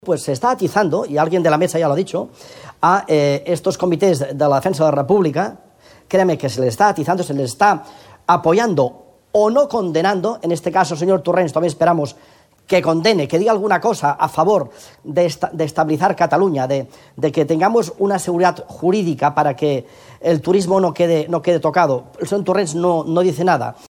L’empresariat afí, en veu de Josep Bou de l’Associació d’Empresaris Catalunya: